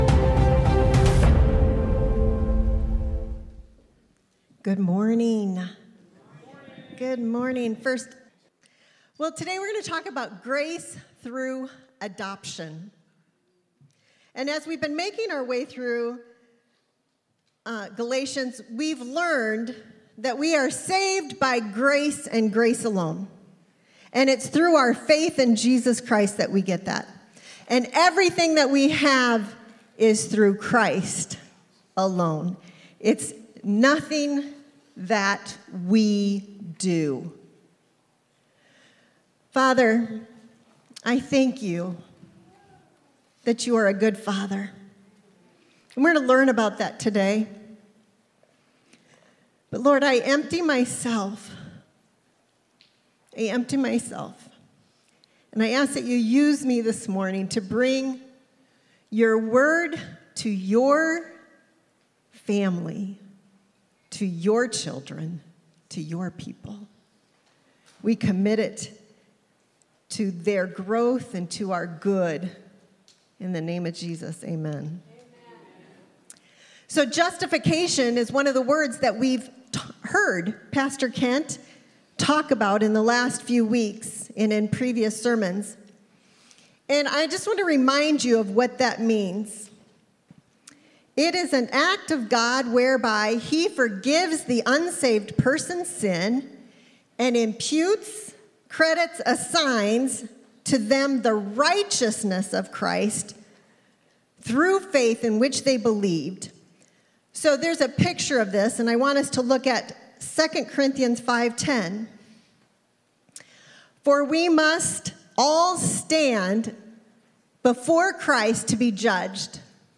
Series: The Book of Galatians Service Type: Sunday